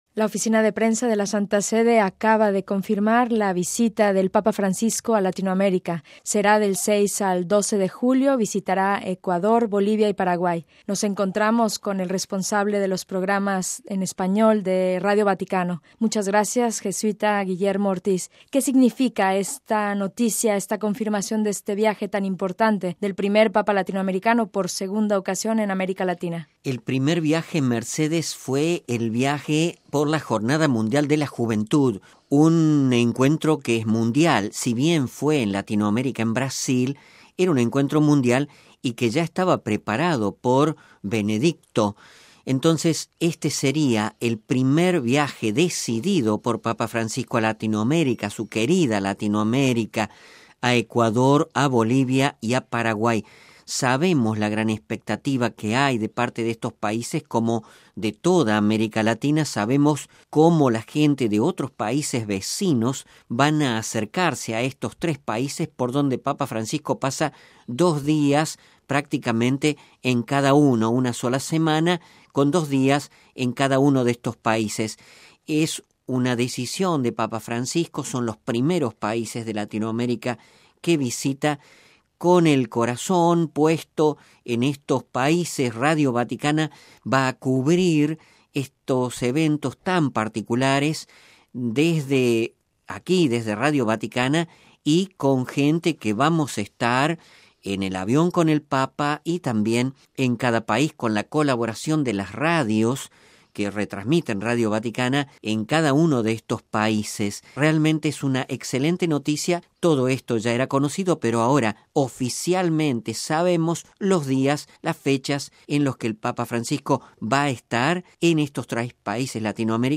Noticia, anuncio de la visita del Papa a Sud América